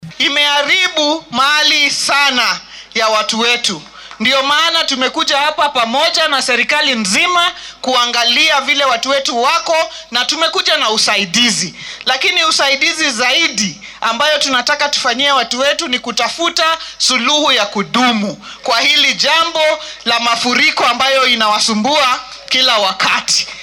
Barasaabka ismaamulka Homa Bay Gladys Wanga oo ka hadashay gargaarka qoysaskan loo fidinaya ayaa dhanka kale xustay inay lagama maarmaan tahay in xal waara loo helo maareynta dhibaatooyinka noocani ah.